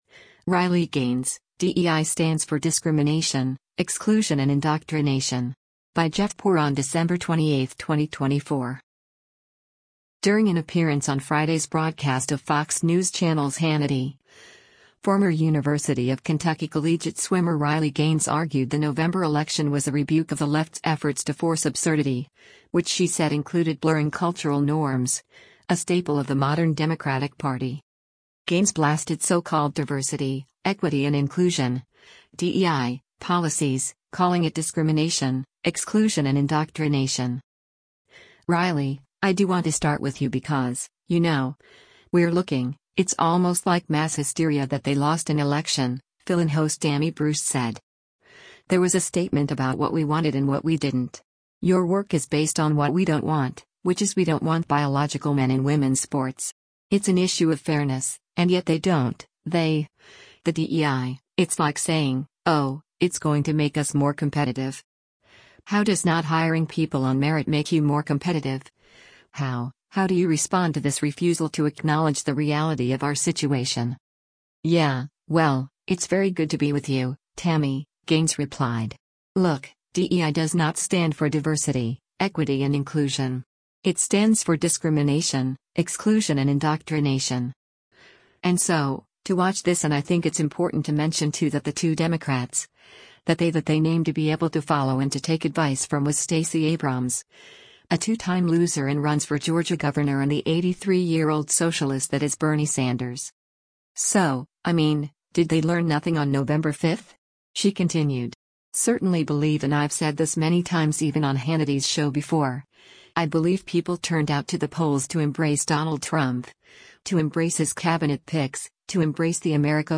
During an appearance on Friday’s broadcast of Fox News Channel’s “Hannity,” former University of Kentucky collegiate swimmer Riley Gaines argued the November election was a rebuke of the left’s efforts to force “absurdity,” which she said included blurring cultural norms, a staple of the modern Democratic Party.